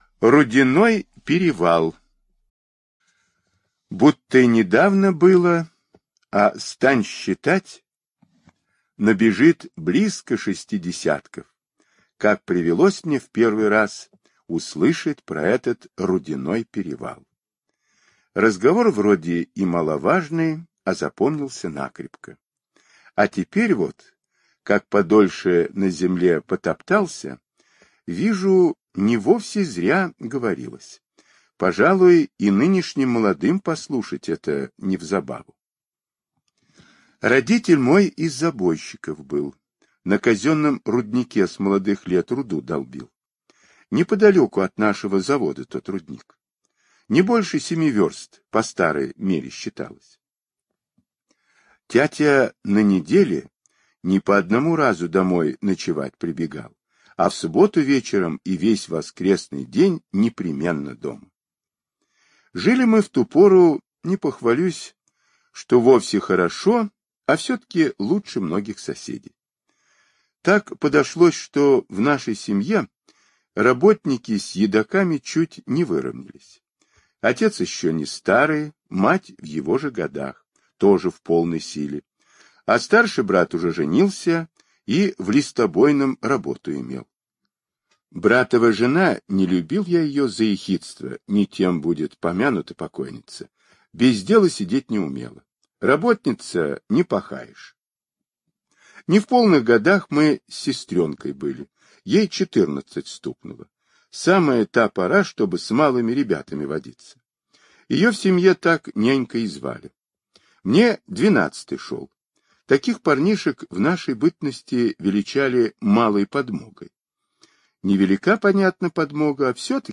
Рудяной перевал - аудиосказка Павла Бажова - слушать онлайн